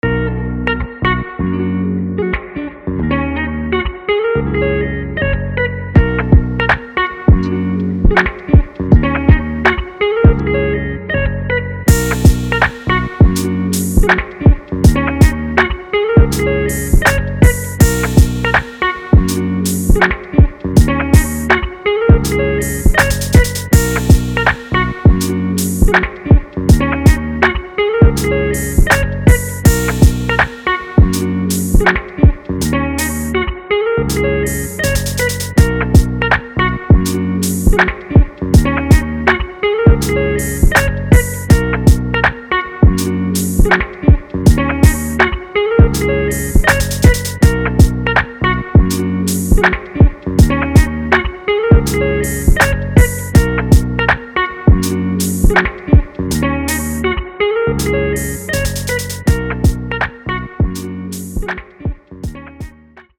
Listen to Hip Hop Sync Music Instrumental